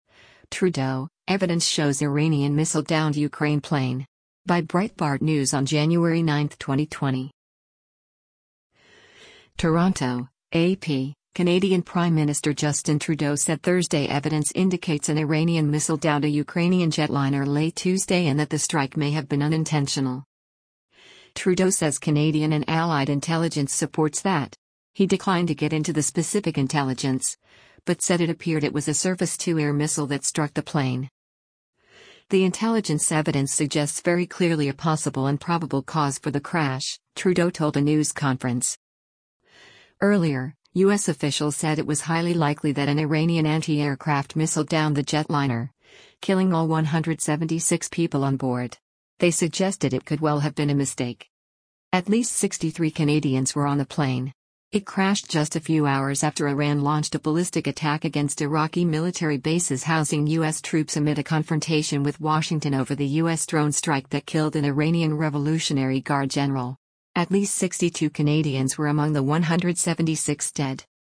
Canadian Prime Minister Justin Trudeau speaks during a news conference January 8, 2020 in